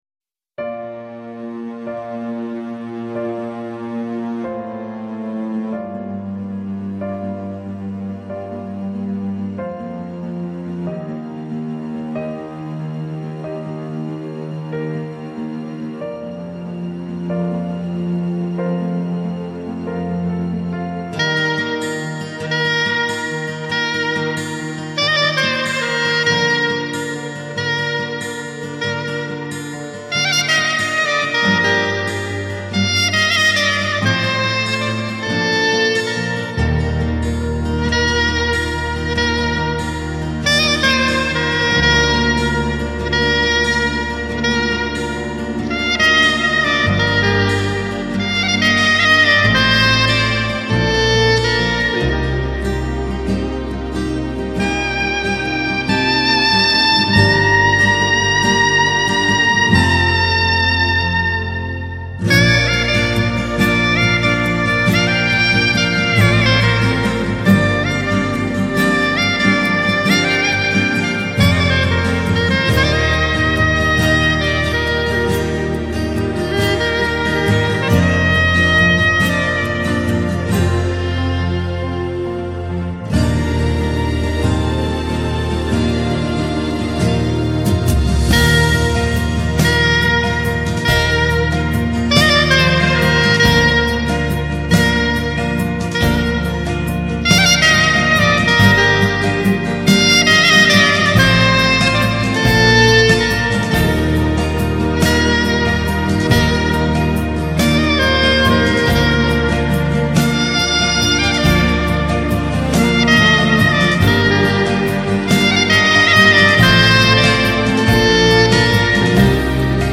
Instrumental Sax Love.